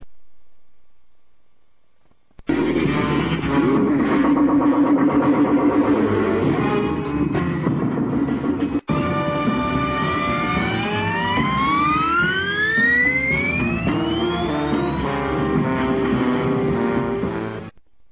The sound effects of the scene alone are enough to make your hair stand on end!.... and with Lindsay Wagners incomparable acting abilities it all comes together in a bone-chilling effect!
Click on the image below to SEE part of this scene as it unfolds! also, the sound-effects are available by clicking on the proper link below the image!